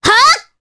Mirianne-Vox_Attack3_jp.wav